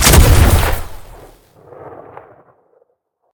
rifle1.ogg